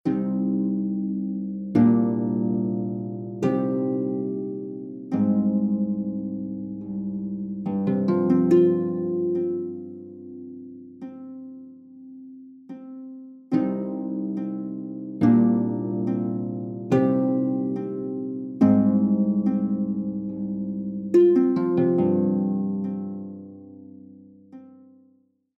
Music for an Imaginary Harp